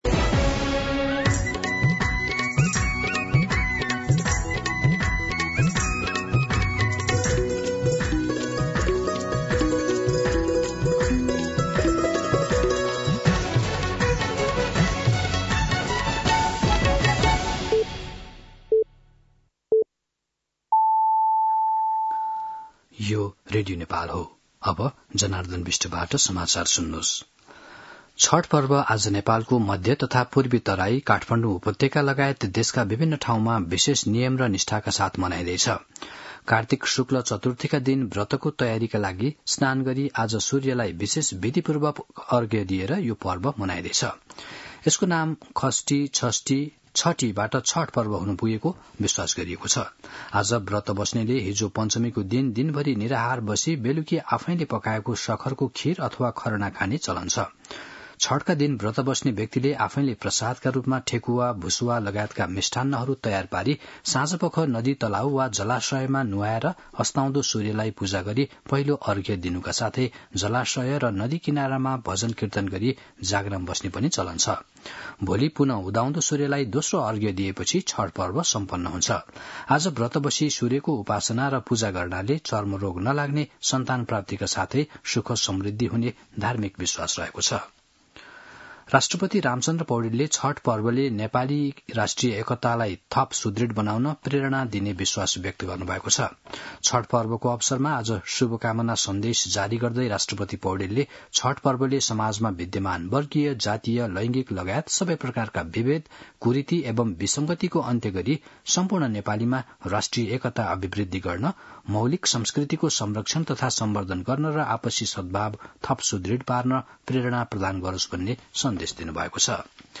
दिउँसो १ बजेको नेपाली समाचार : १० कार्तिक , २०८२
1-pm-Nepali-News-13.mp3